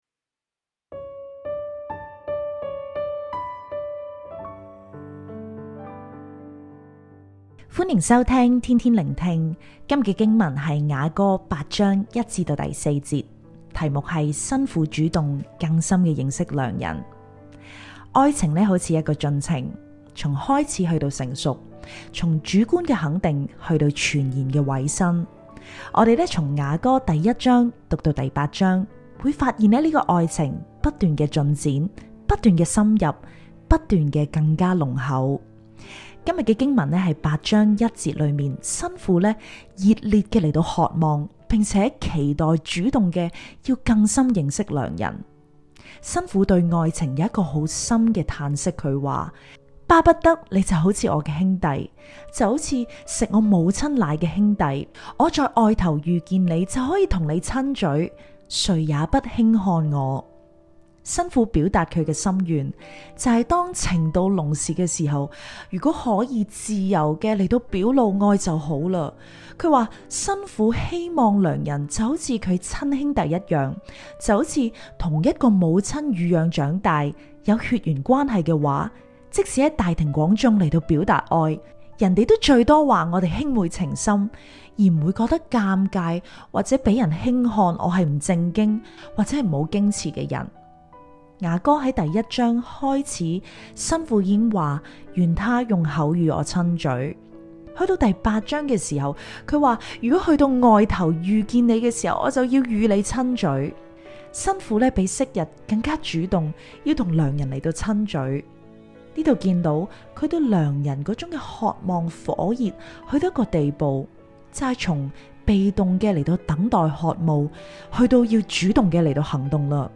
普通話錄音連結🔈